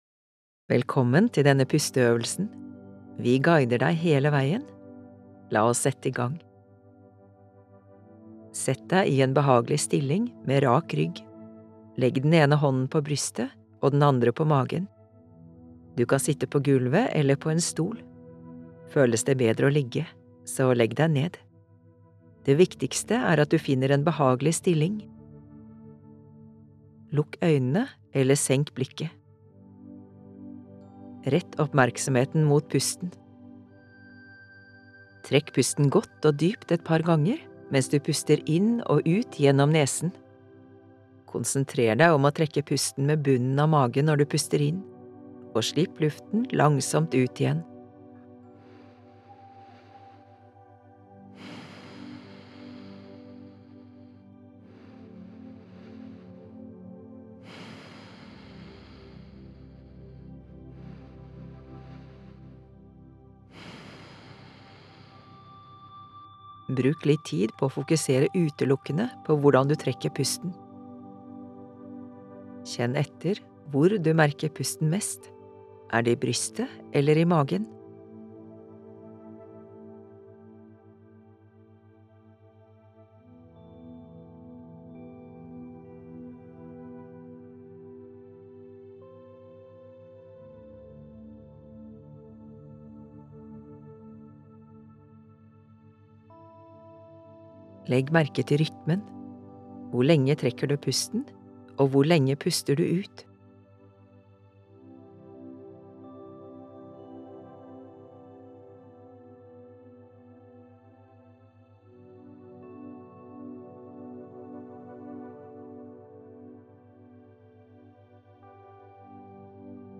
Det bevisste åndedrettet – pusteøvelse med lydguide
• Først får du en intro til øvelsen.
• Deretter får du 3 min til å fortsette pusteøvelsen i ditt eget tempo.